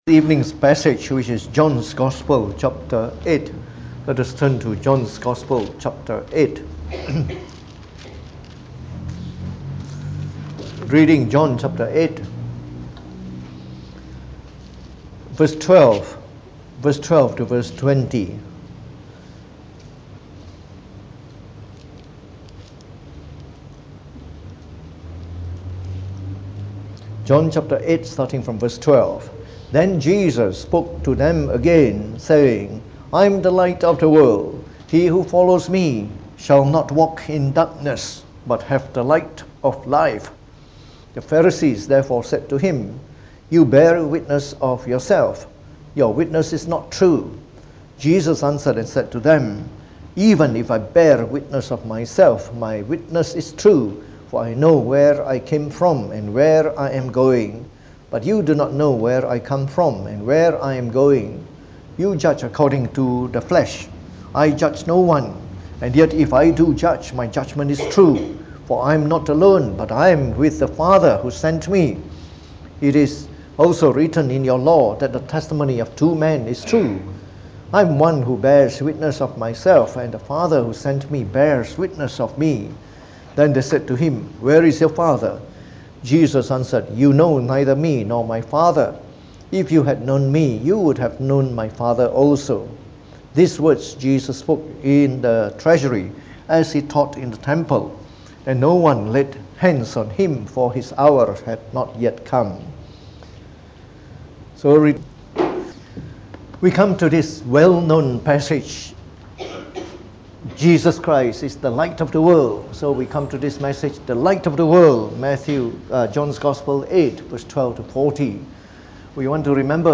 Preached
delivered in the Evening Service